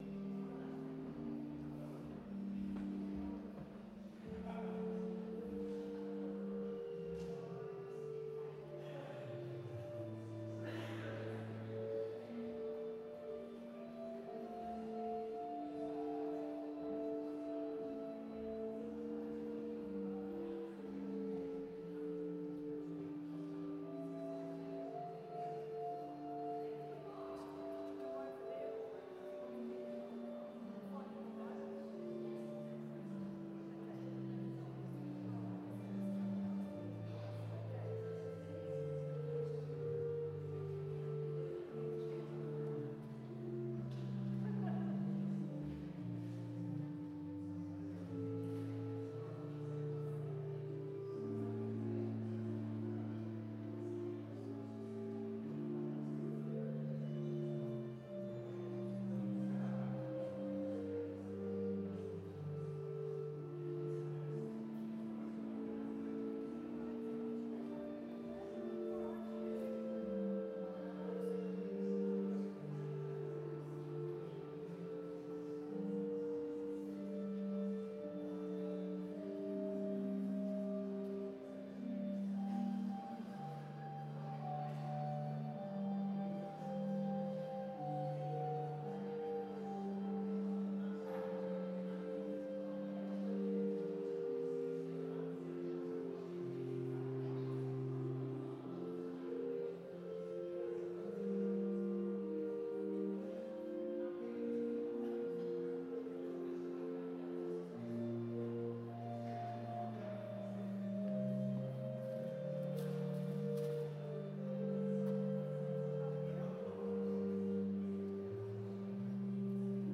Sermon Only Audio